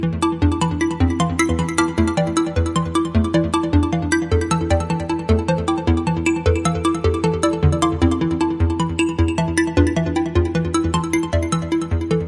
描述：动作嗡嗡声
Tag: 合成器 环路 模拟